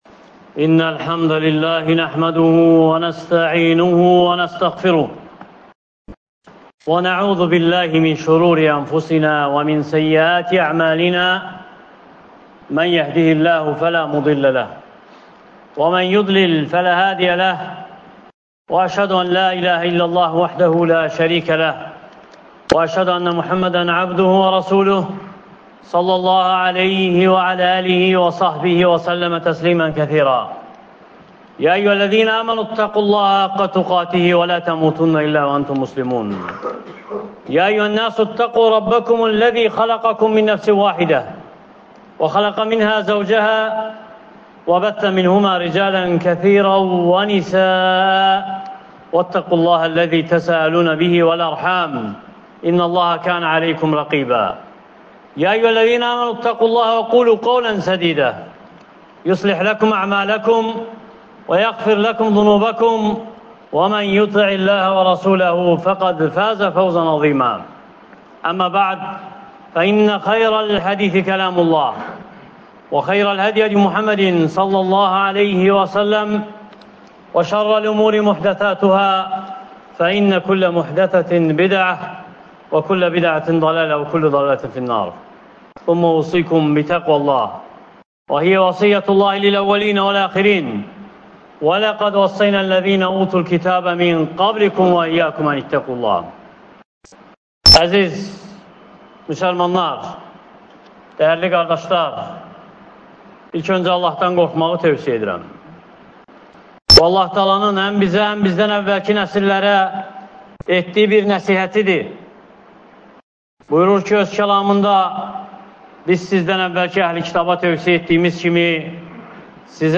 Həccdən sonra halın necə olmalıdır? (Cümə xütbəsi — 05.07.2024) | Əbu Bəkr məscidi